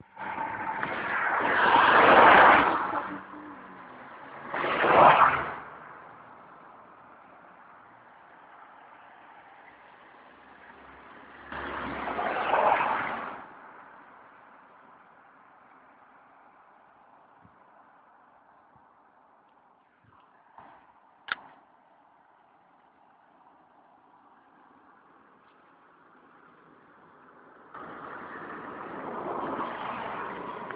Rue Auguste Renoir
Voitures qui passent